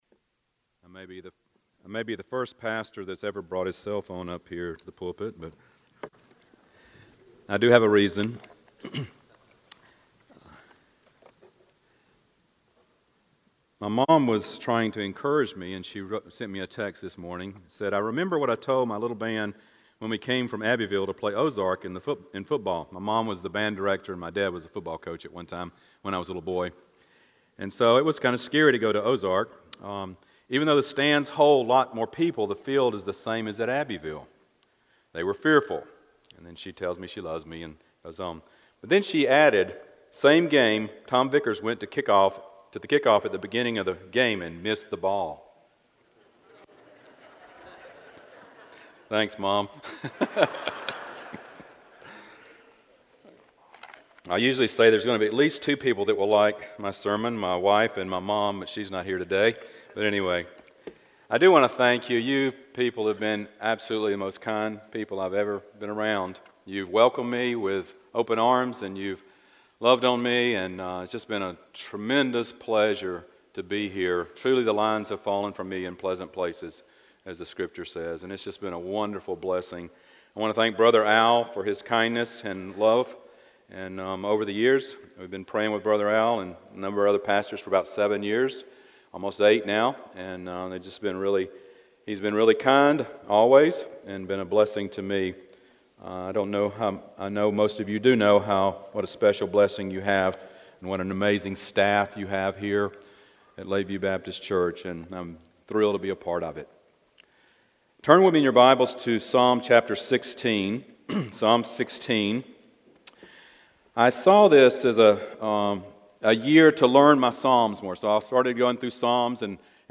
Stand Alone Sermons Passage: Psalm 16:1-11 Service Type: Sunday Morning 1.